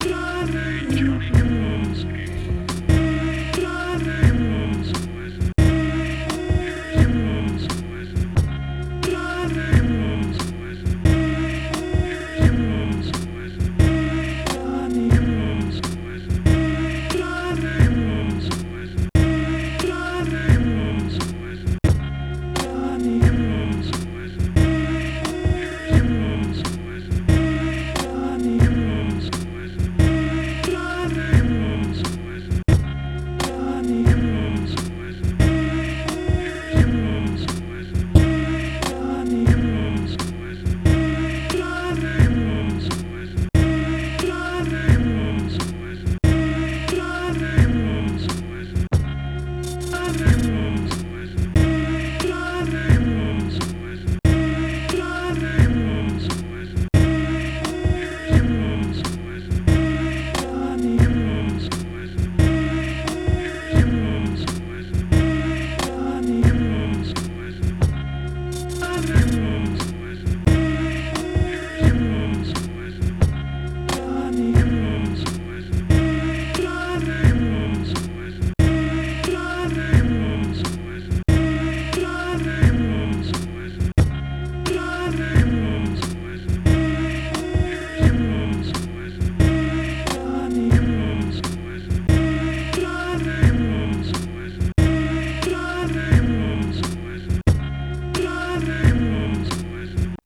ビート再現練習